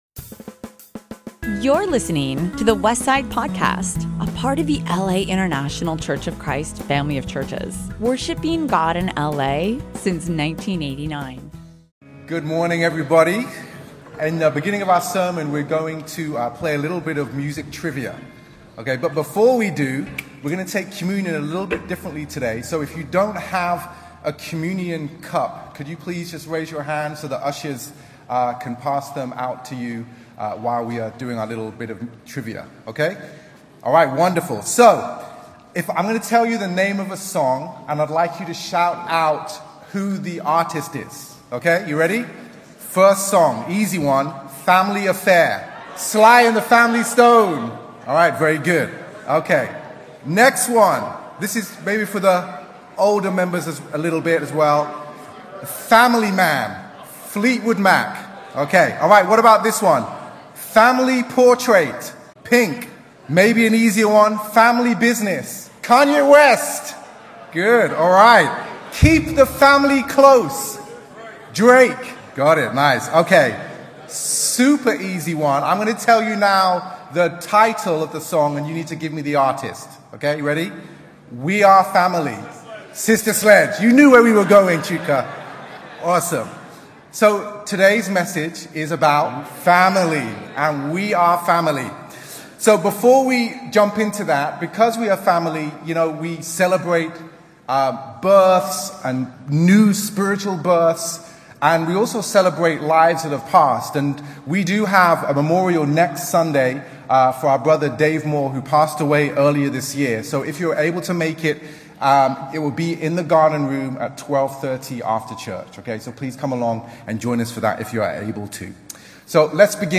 Past Sermons | The Westside Church - Los Angeles, CA